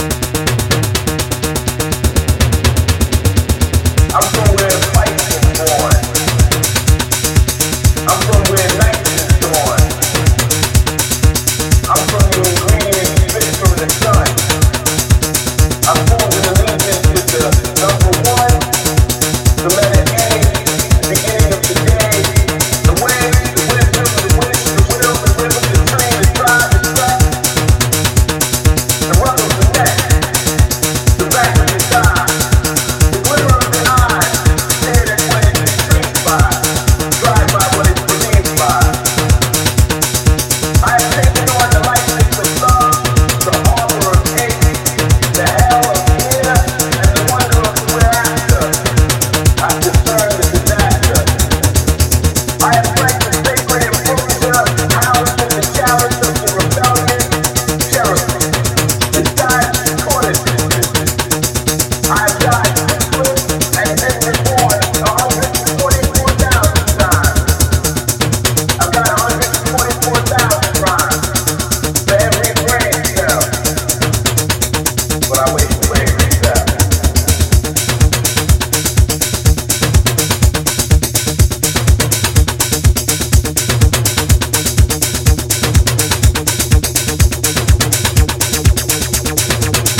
supplier of essential dance music
House Techno